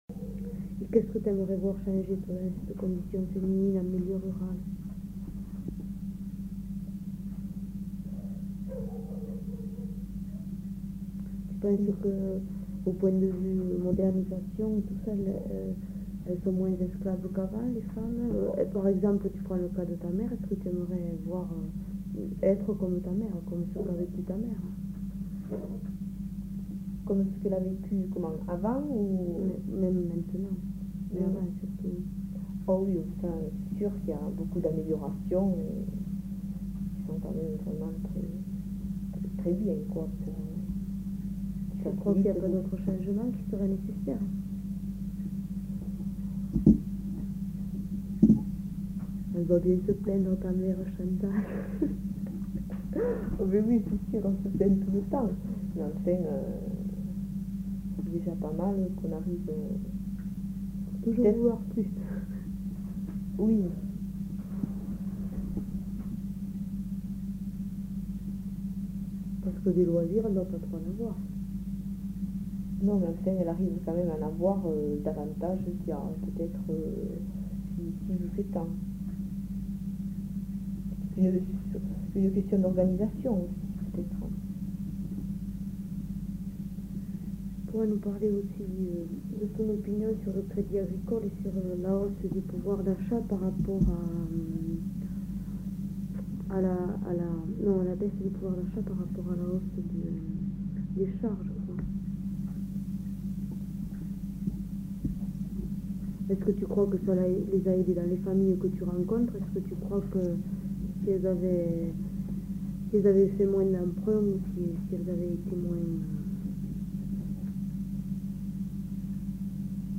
Lieu : Garravet
Genre : témoignage thématique